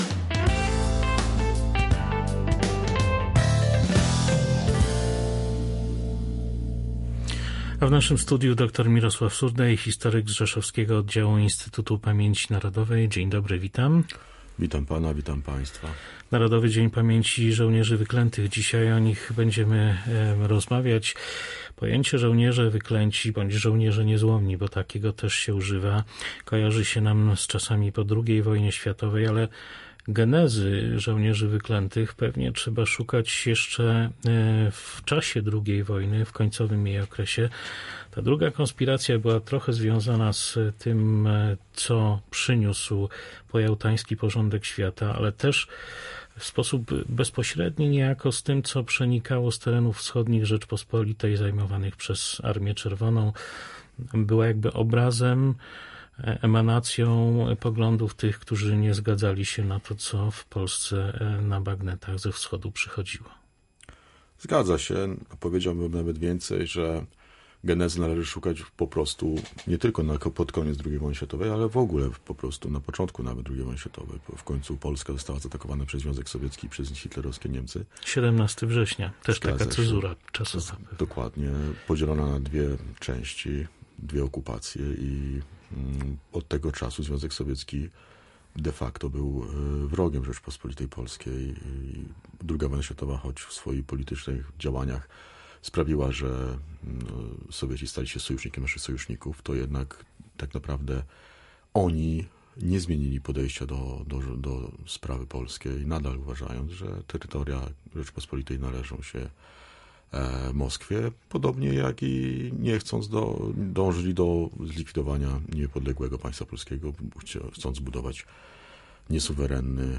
– podkreśla gość Polskiego Radia Rzeszów.